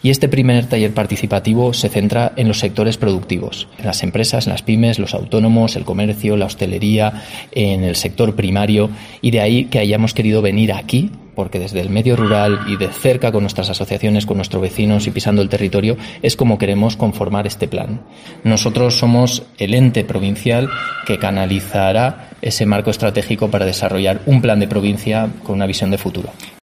Isaac Claver, presidente de la DPH